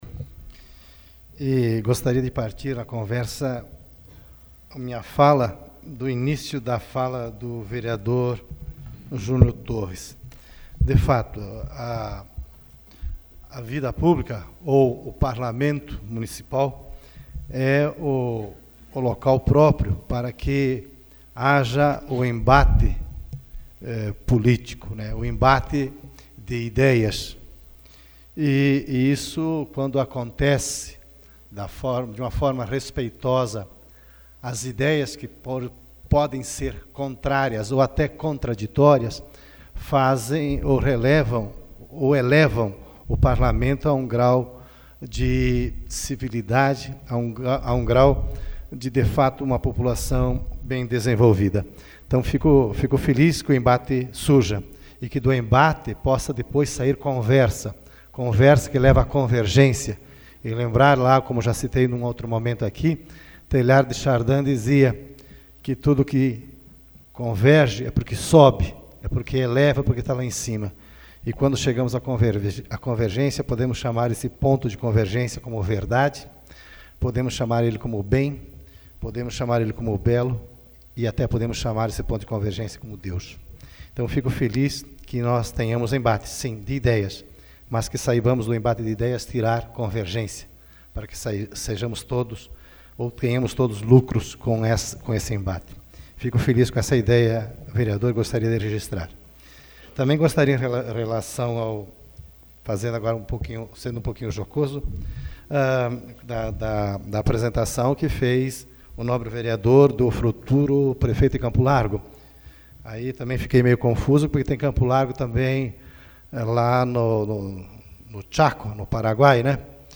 SAPL - Câmara Municipal de Campo Largo - PR
Explicações pessoais AVULSO 08/04/2014 Luiz Rossatto